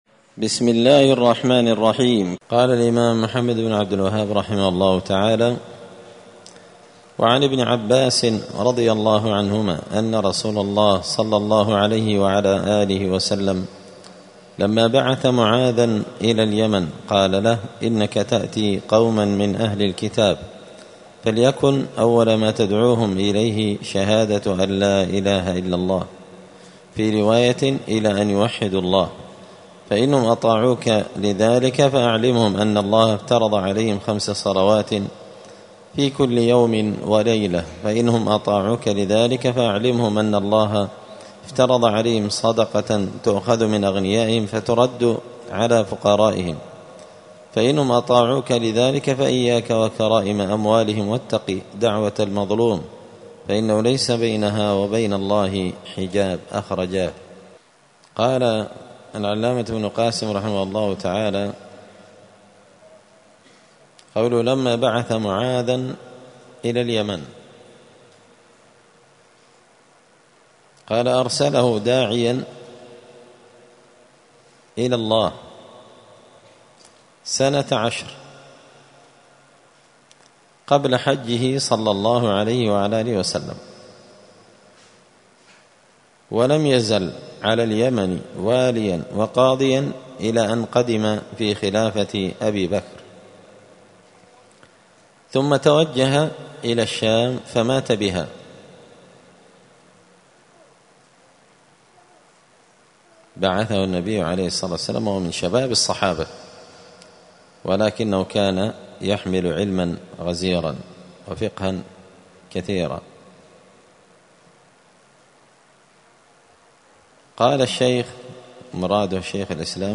دار الحديث السلفية بمسجد الفرقان بقشن المهرة اليمن
*الدرس العشرون (20) {تابع للباب الخامس باب الدعاء إلى شهادة أن لا إله إلا الله…}*